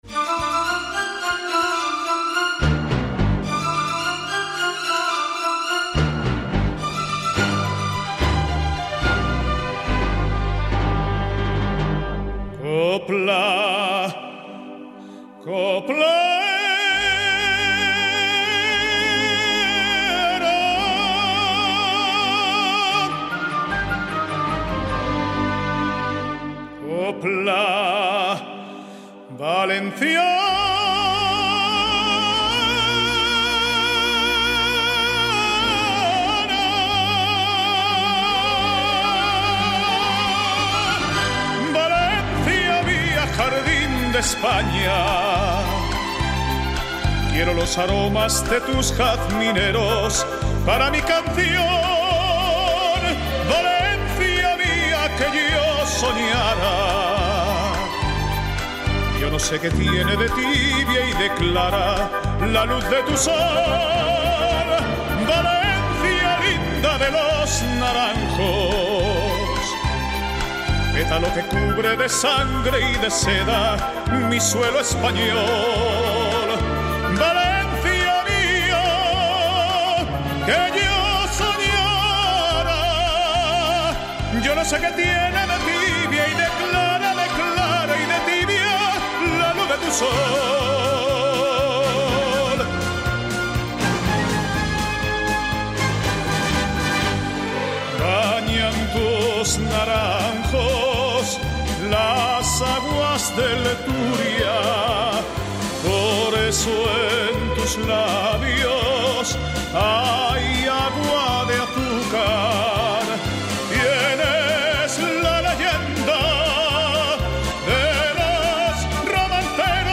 entrevista-tlf-alcalde-valencia.mp3